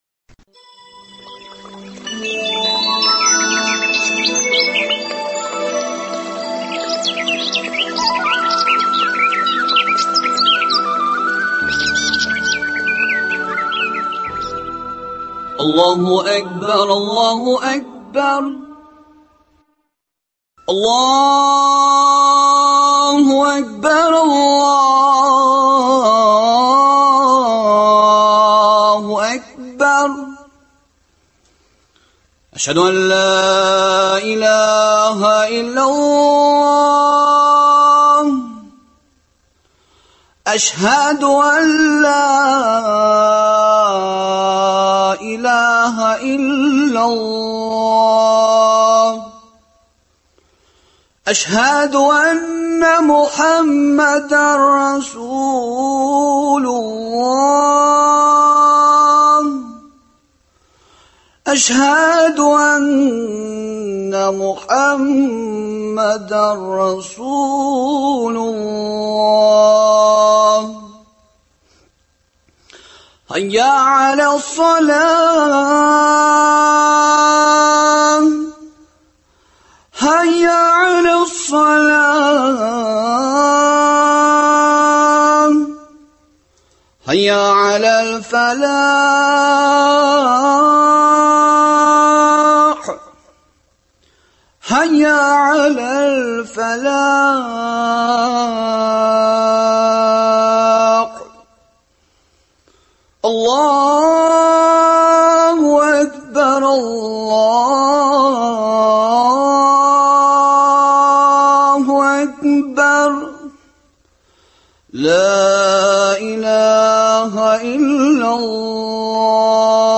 Тапшыруыбызның икенче өлеше дини тормышыбыздан һәм ататар дөньясыннан хәбәрләр белән ачылып китә. Аннары тыңлаучыларыбыз игътибарына шагыйрь Харрас Әюповның “Биш вакыт намаз” поэмасына нигезләнеп эшләнгән радиокомпозиция тәкъдим ителә.